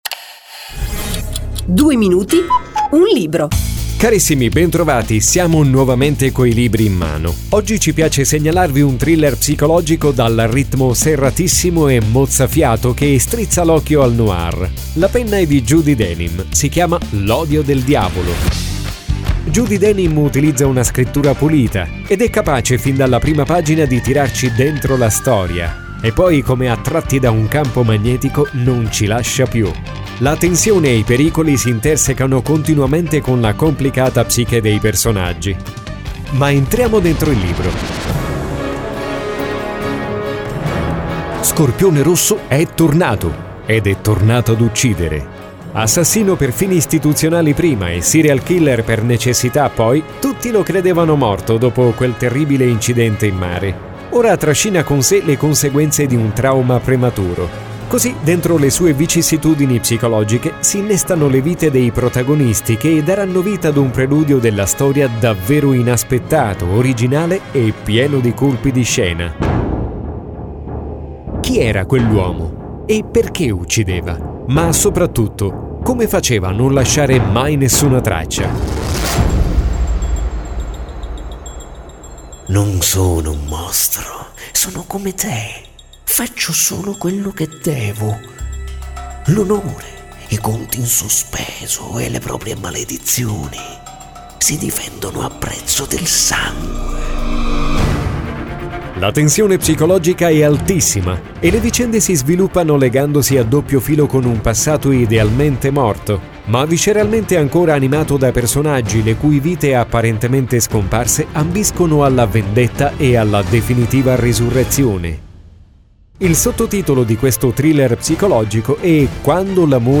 Leggi i primi capitoli L'ODIO DEL DIAVOLO è reperibile su AMAZON Radio Ascolta la puntata trasmessa in radio